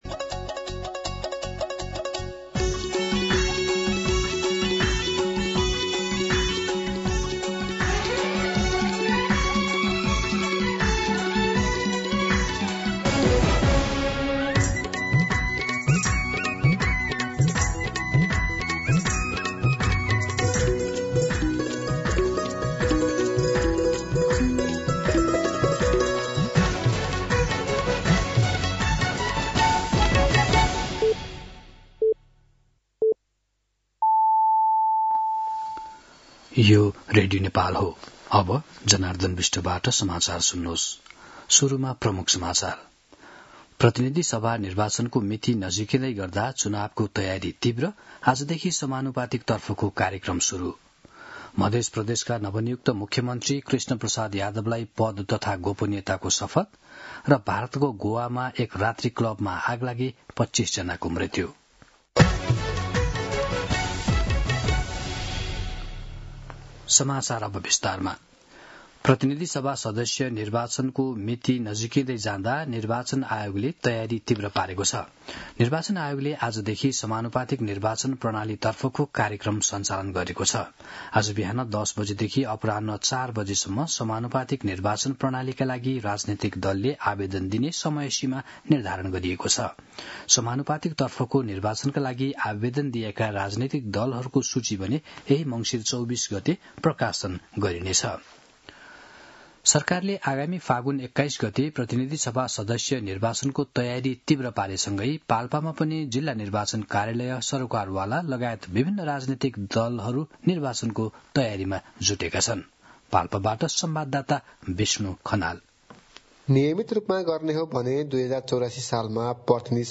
दिउँसो ३ बजेको नेपाली समाचार : २१ मंसिर , २०८२